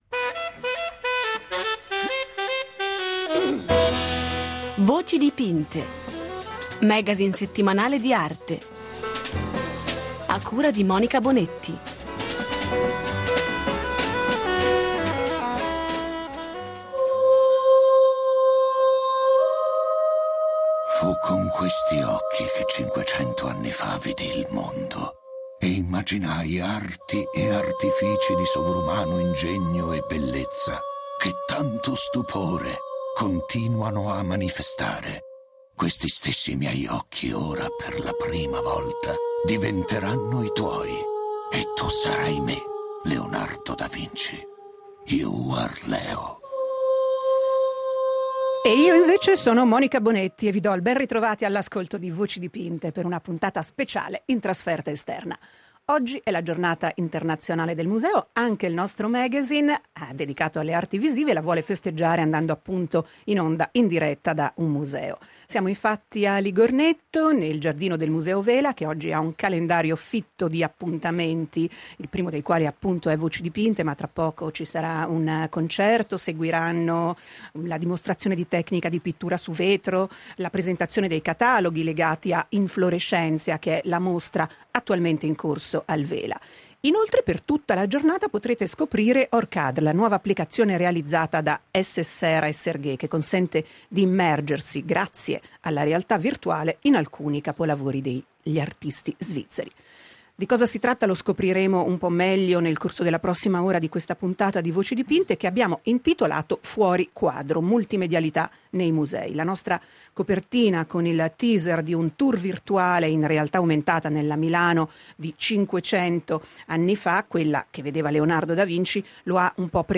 Multimedialità nei musei, in diretta dal Museo Vincenza Vela